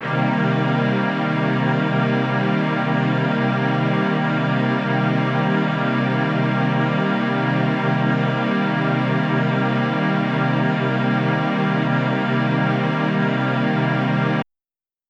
SO_KTron-Ensemble-Cmin7.wav